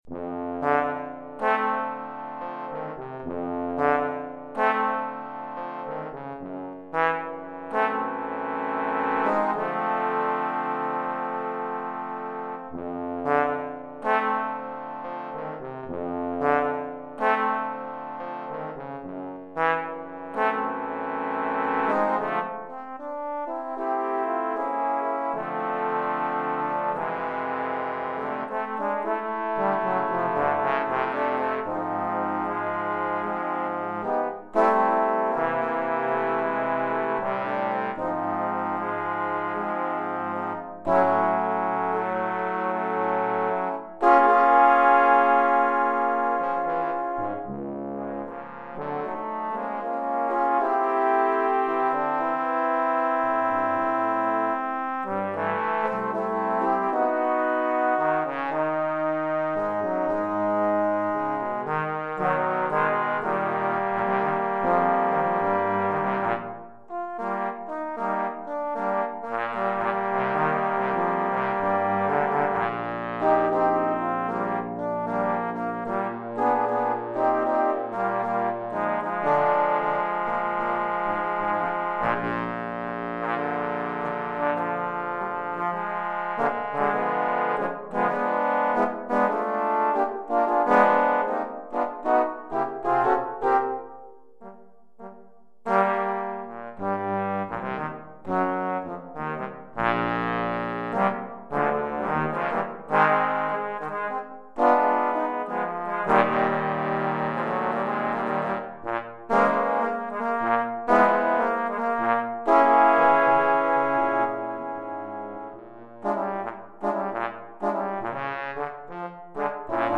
4 Trombones